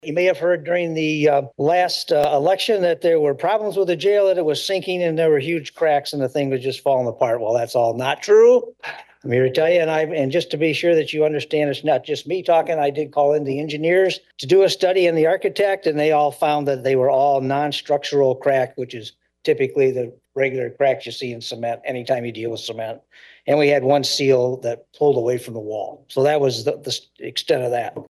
Sheriff Pollack gives final report to Coldwater City Council
Pollack appeared before the Coldwater City Council this past Monday night and tried to dispel recent talk about the new Branch County Jail.